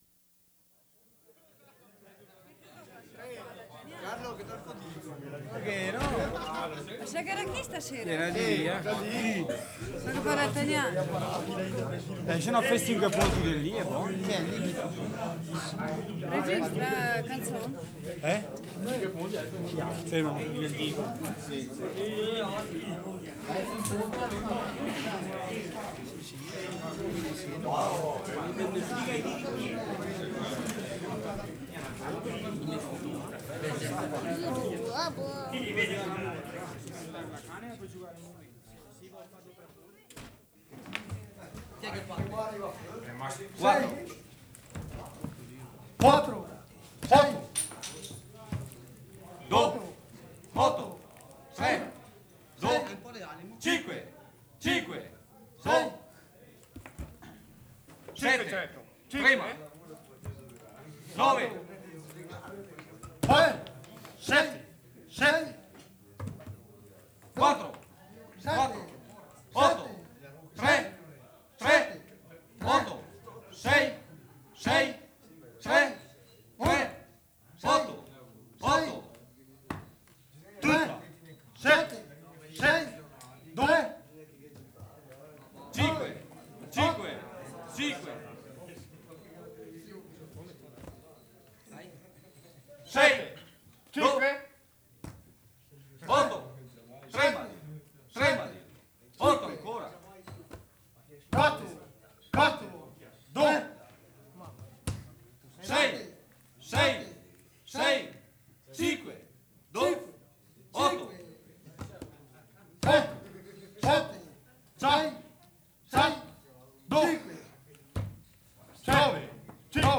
Morra Game, Cembra, Italy 7.